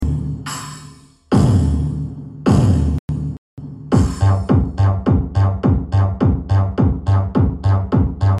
Speaker bass Heart-shaking, 8-inch Harman